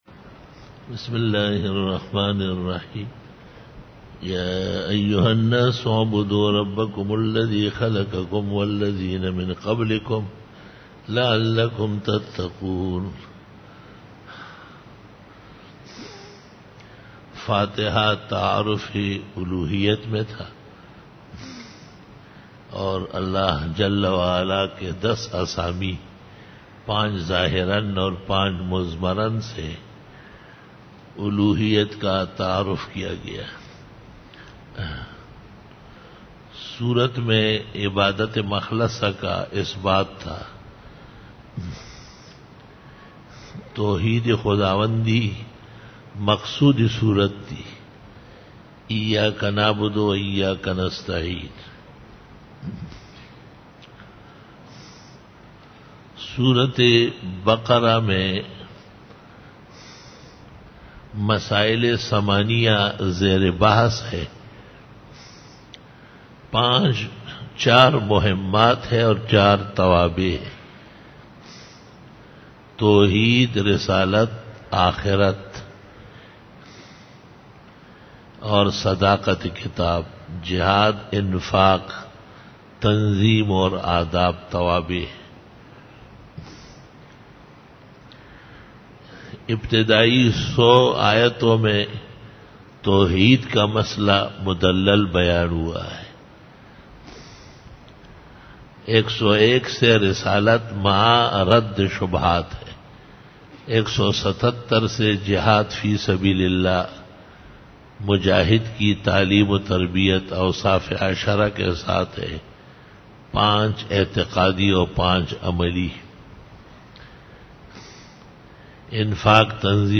دورہ تفسیر